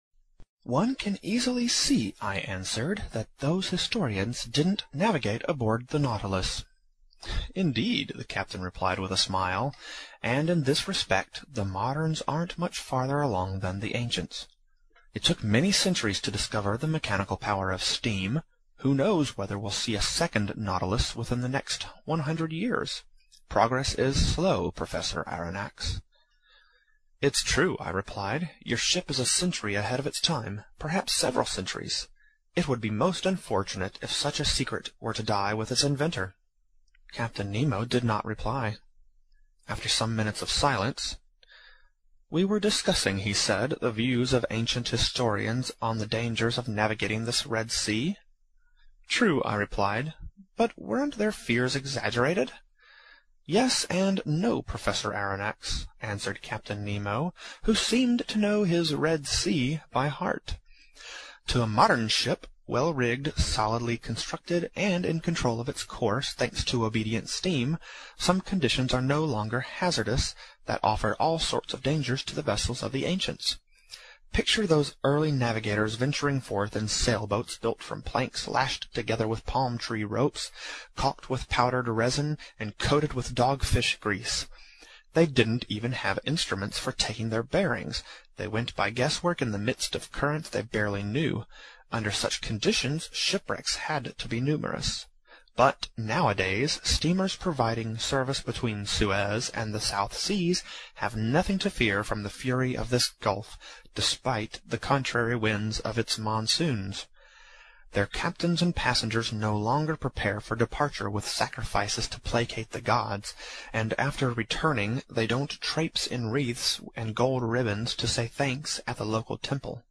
在线英语听力室英语听书《海底两万里》第347期 第23章 珊瑚王国(46)的听力文件下载,《海底两万里》中英双语有声读物附MP3下载